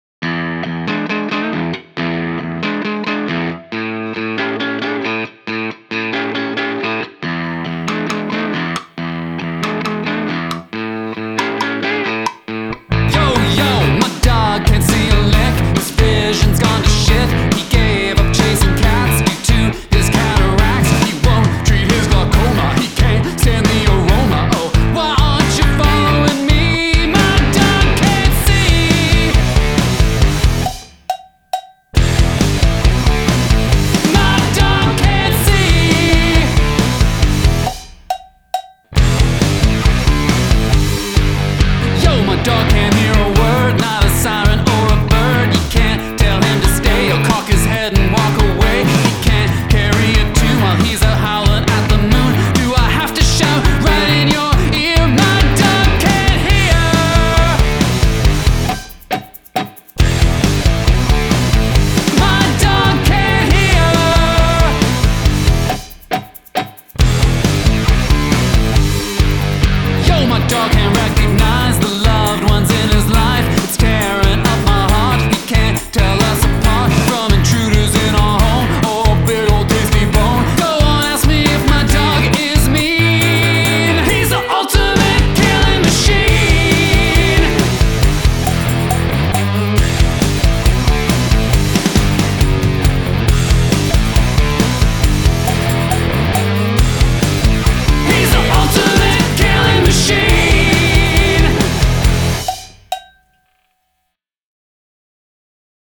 Hand percussion
Rocking as always. Cowbell?